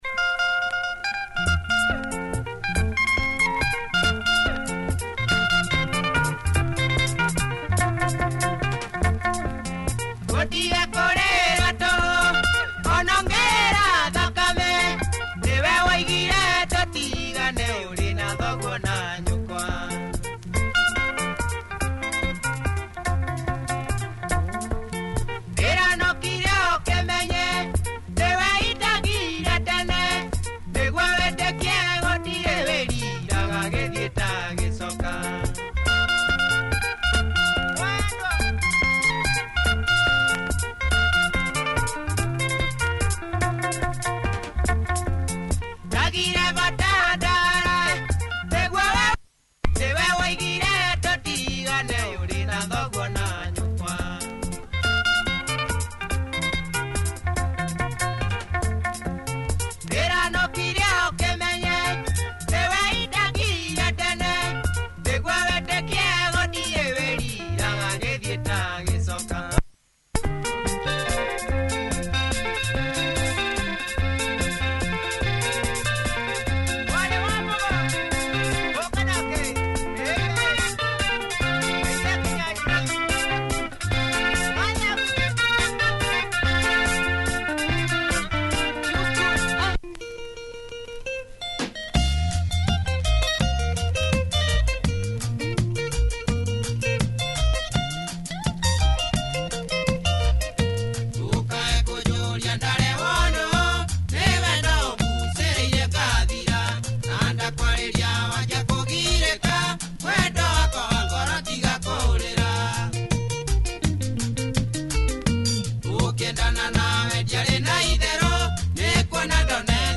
Nice punchy Kikuyu benga